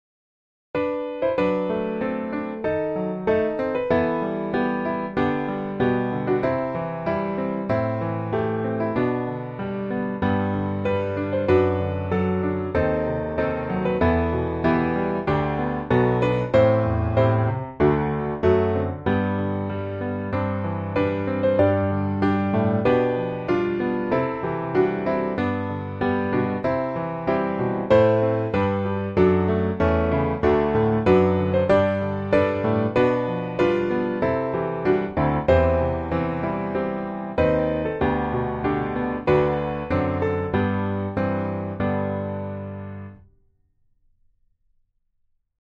432_MusicOnly.mp3